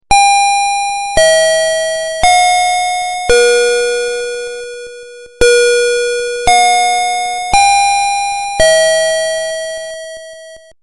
03. Door Bell III
• Dual tone melody
• 3 kinds of songs (Ding-Dong, Ding-Dong/Ding-Dong and Westminster chime)